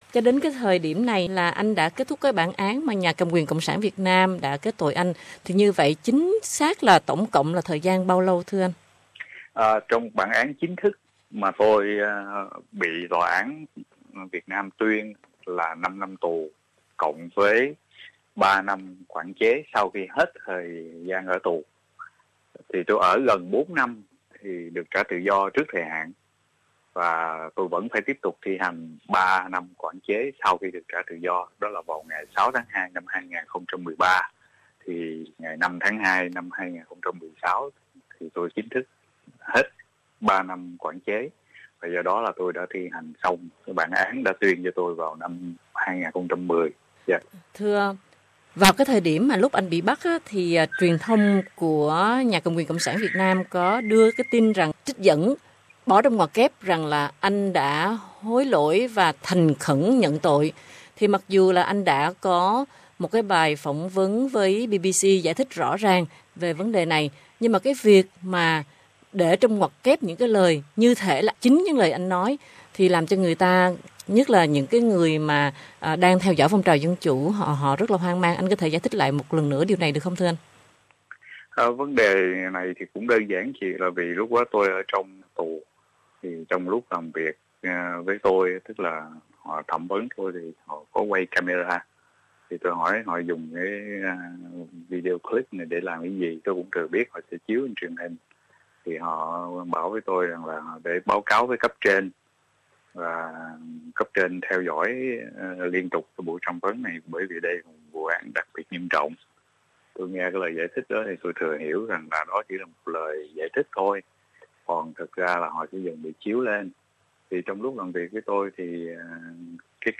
Trò chuyện đầu năm với Luật sư Lê Công Định